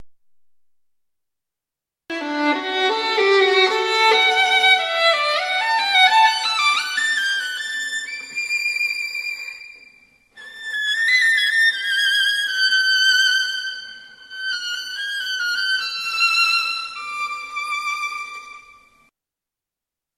4. Finalmente, o é o membro máis pequeno, afamado polo seu virtuosismo e rapidez.
Violin.mp3